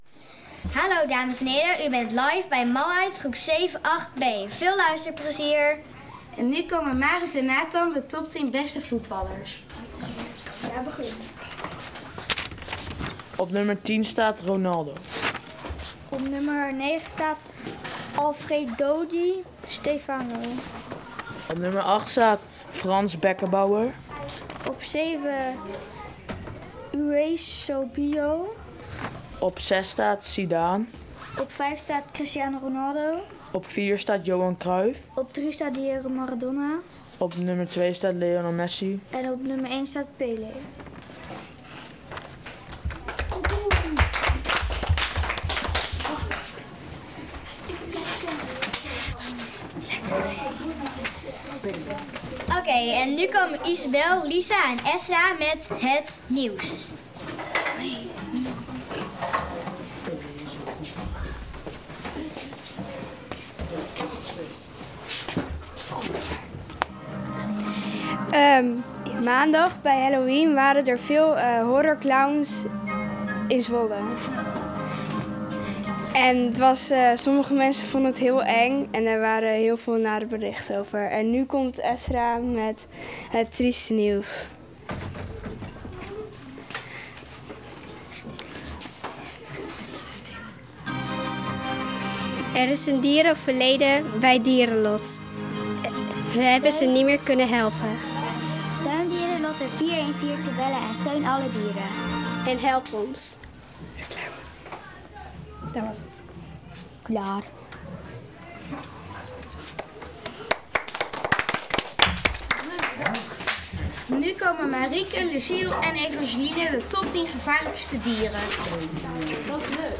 Het nieuws gepresenteerd met eigen gecomponeerde muziek!!